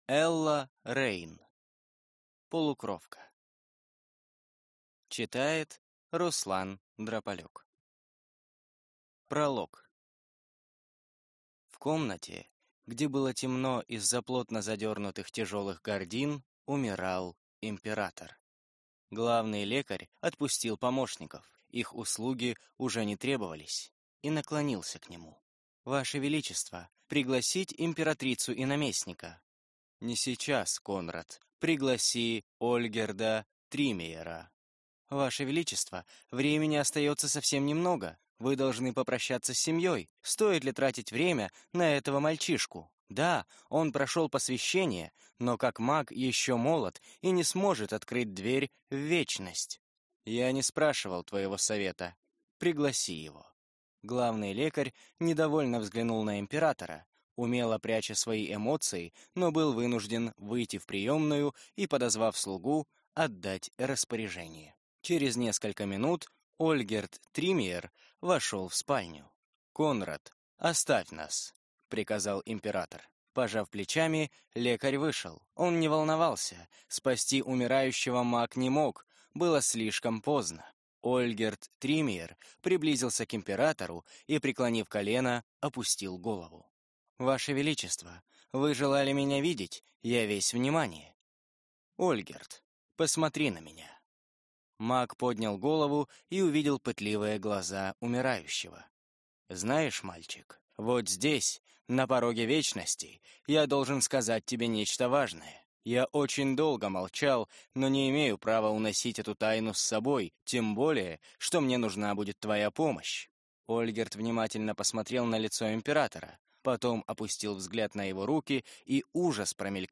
Аудиокнига Полукровка | Библиотека аудиокниг